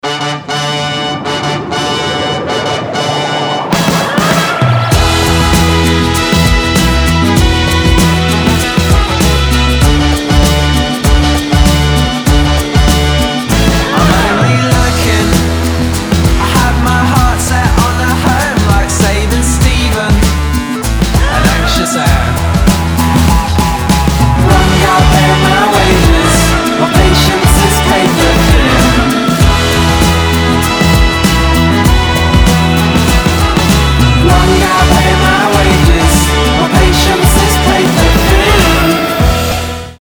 • Качество: 320, Stereo
alternative
indie rock
Позитивный саунд из футбольного симулятора